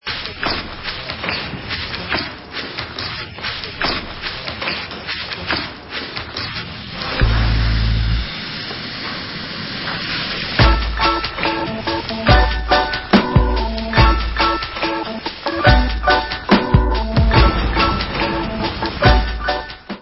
sledovat novinky v oddělení Experimentální hudba